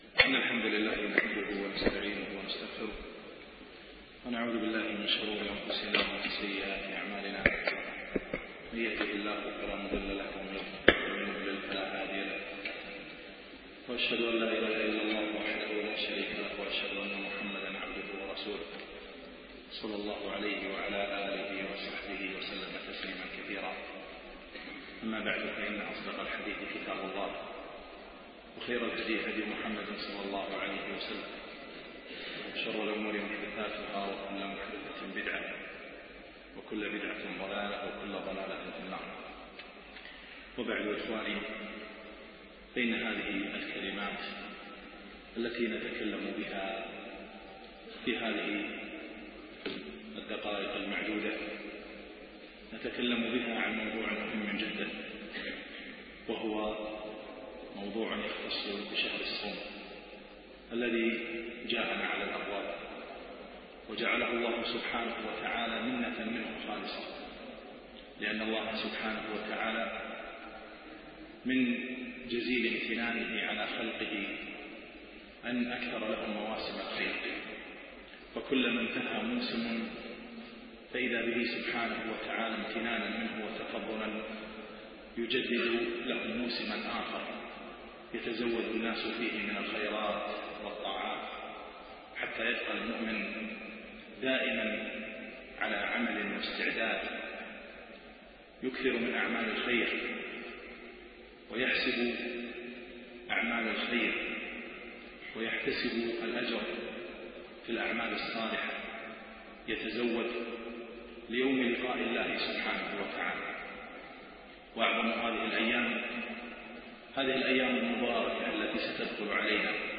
من دروس الشيخ في دولة الإمارات